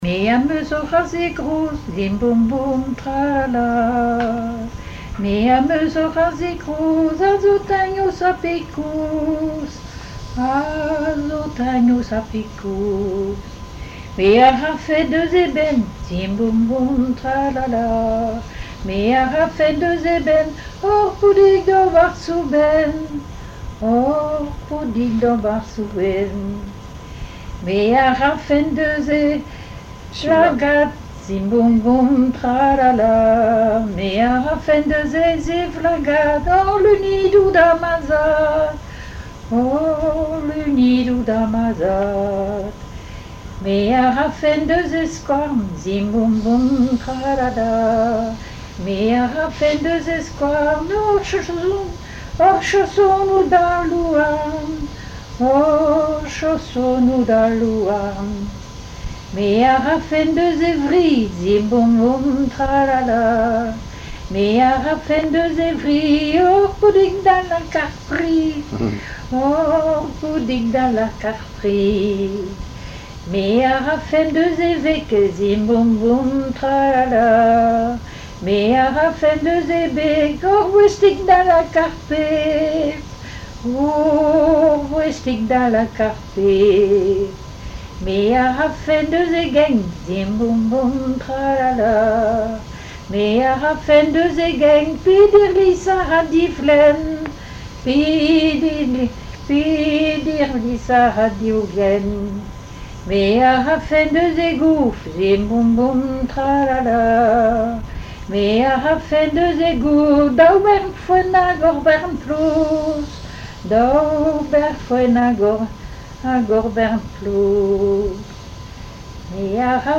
Genre strophique
Chansons en breton
Pièce musicale inédite